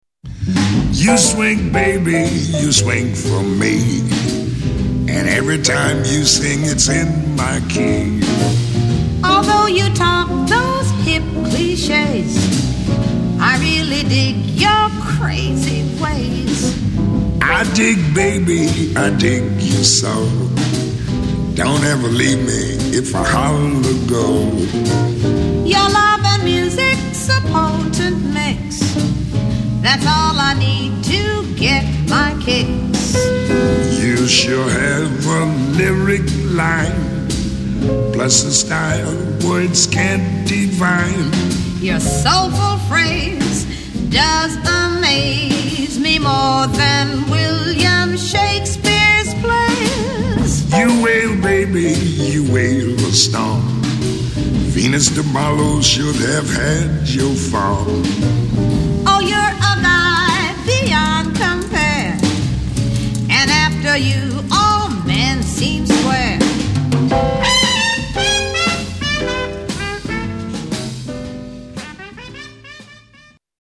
bass
drums
vocal trio